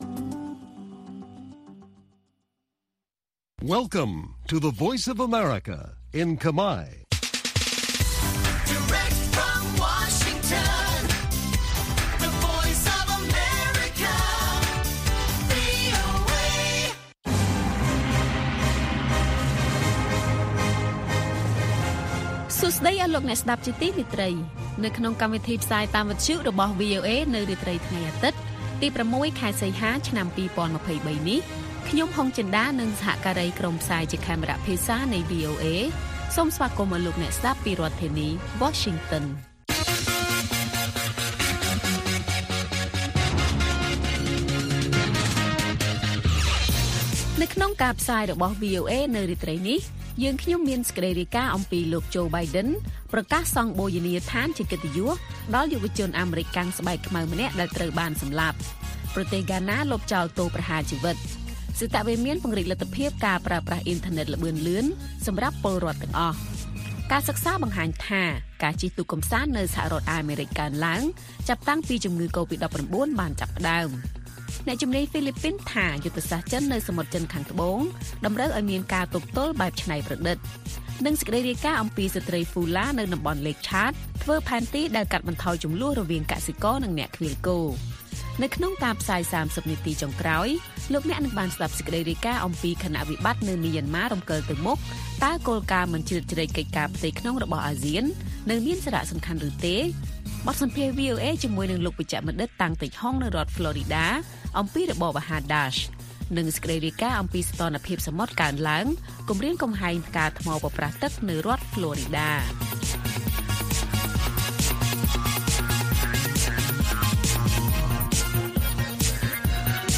ព័ត៌មានពេលរាត្រី ៦ សីហា៖ លោក Biden ប្រកាសសង់បូជនីយដ្ឋានជាកិត្តិយសដល់យុវជនអាមេរិកាំងស្បែកខ្មៅម្នាក់ ដែលត្រូវបានសម្លាប់